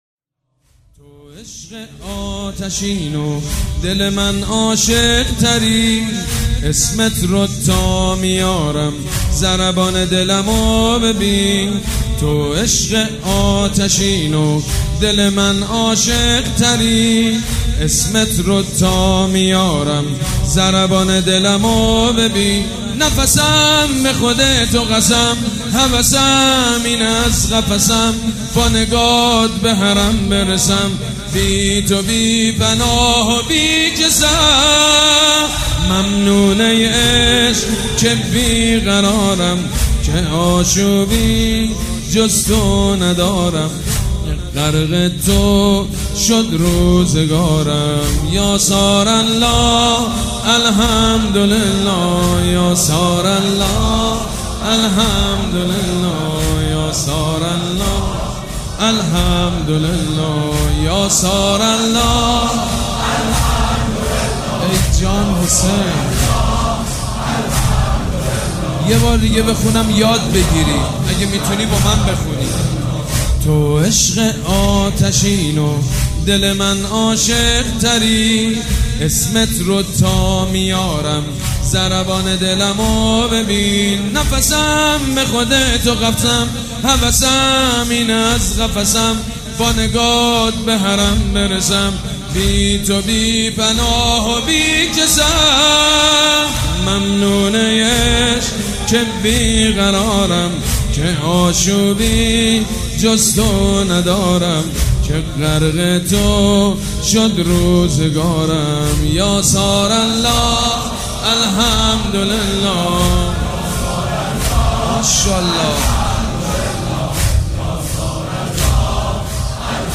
مرثیه سرایی
روضه